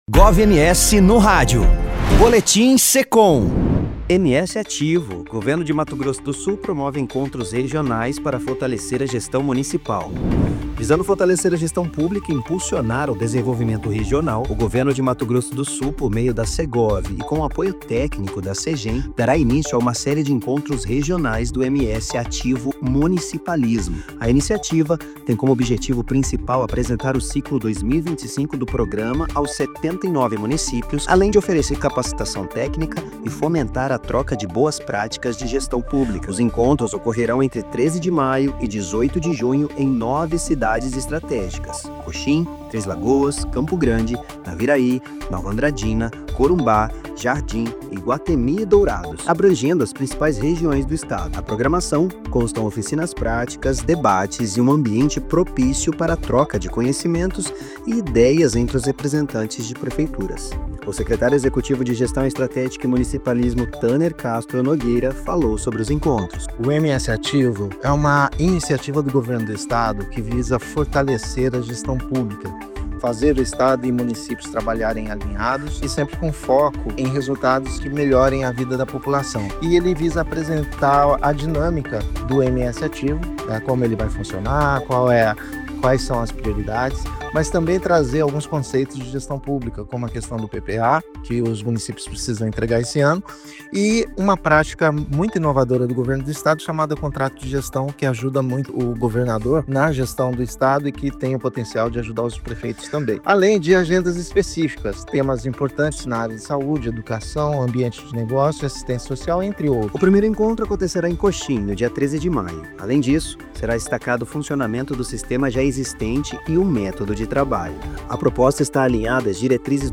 Boletim Municipalismo
Boletim-Municipalismo.mp3